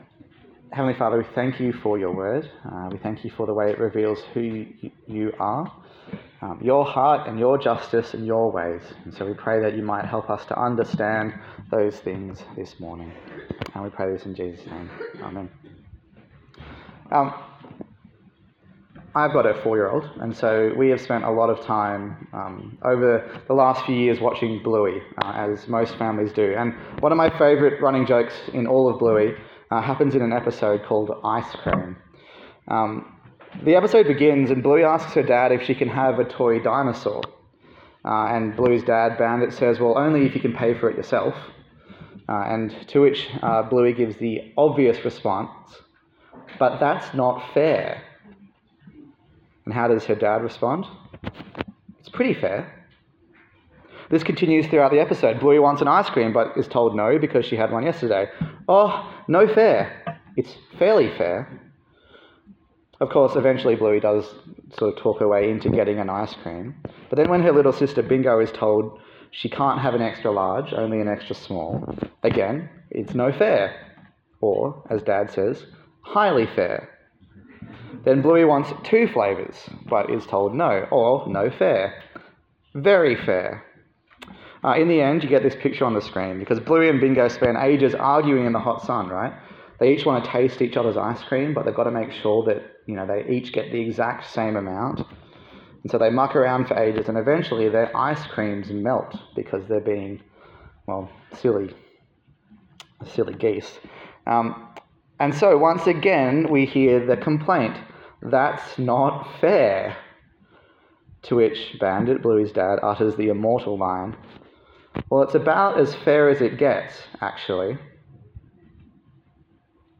A sermon in the series on Ezekiel
Ezekiel Passage: Ezekiel 18 to 20 Service Type: Sunday Morning